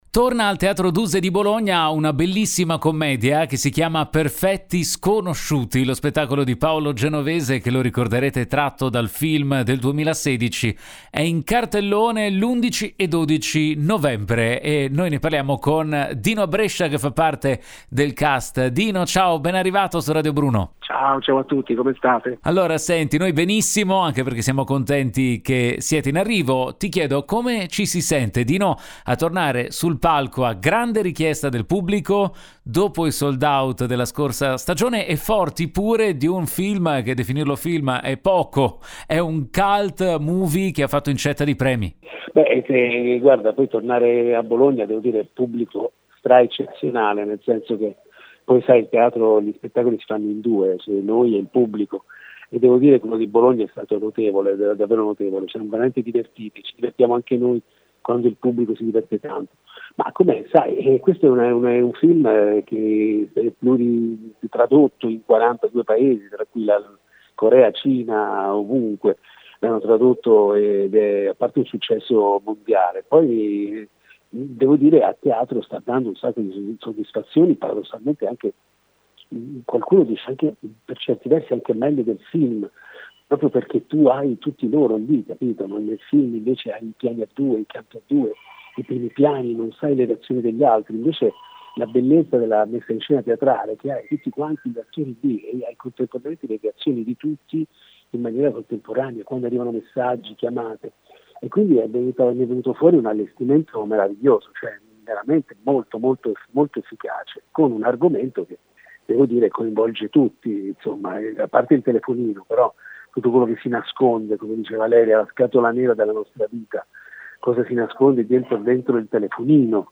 Home Magazine Interviste “Perfetti sconosciuti” in scena al Duse di Bologna
Nel cast anche Dino Abbrescia.